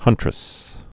(hŭntrĭs)